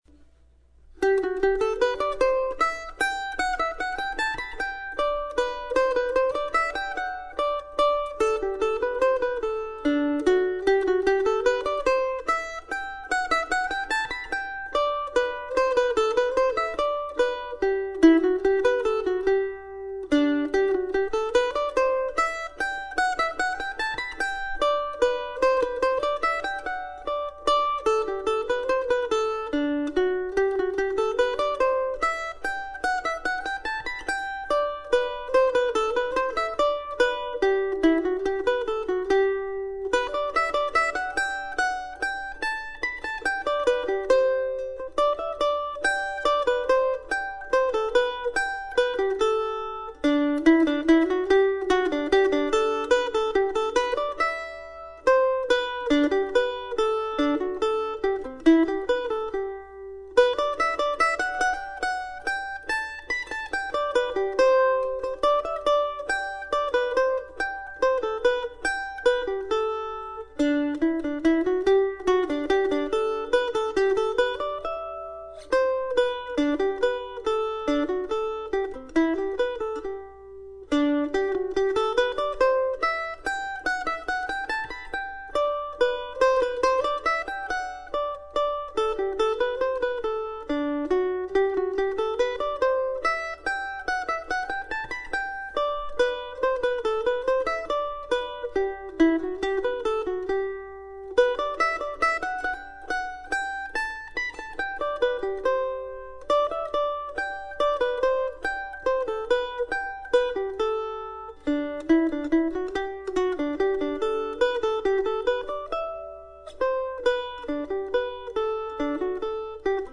This recording is a version for solo mandolin and I played this tune last night during a fun 2 hour solo mandolin gig at Java John's Coffee House here in Decorah.